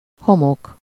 Ääntäminen
France (Paris): IPA: [y.n‿a.ʁɛn]